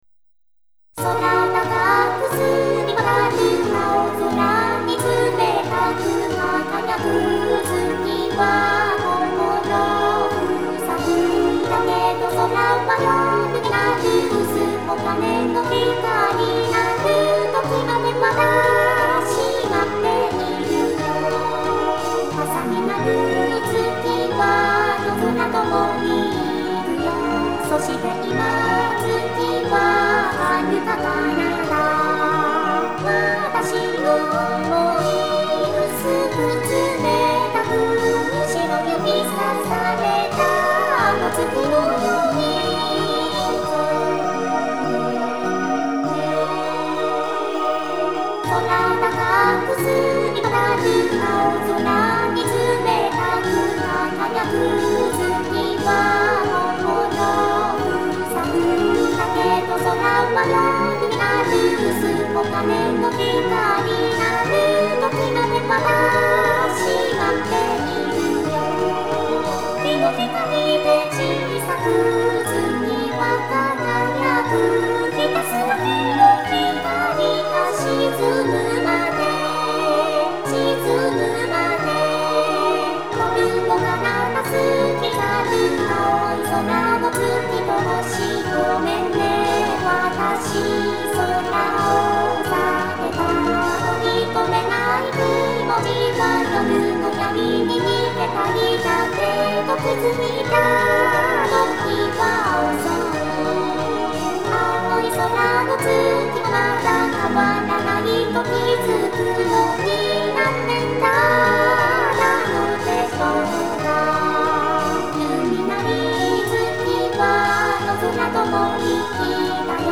〜ボーカル版〜